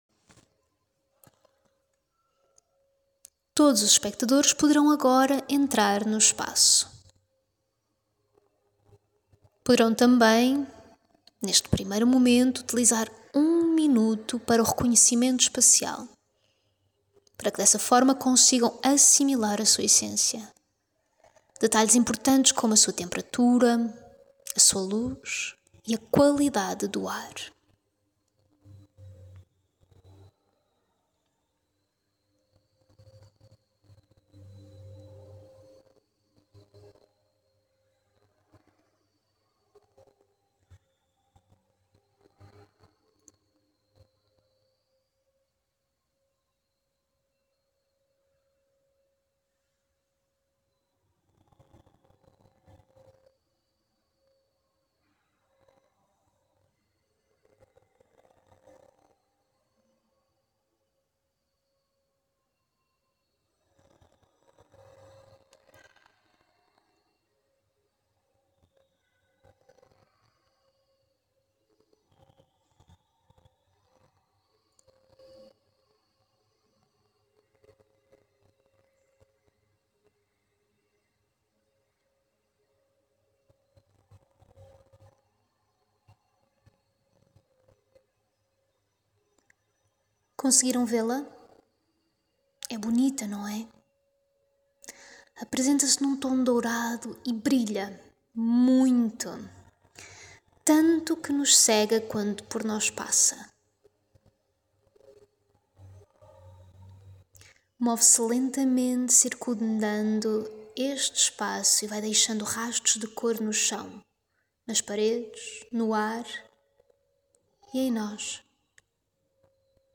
en Audiodescription